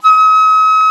FLT FL D#6.wav